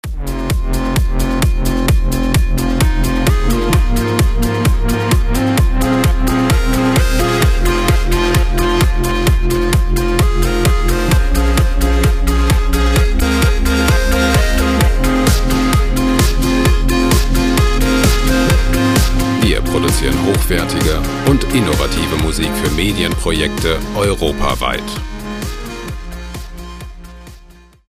freie Techno Loops
Musikstil: EDM
Tempo: 126 bpm